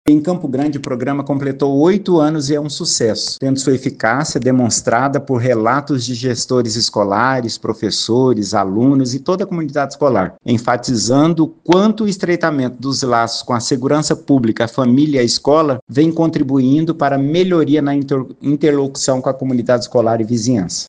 Em entrevista ao programa da FM Educativa MS “Agora 104”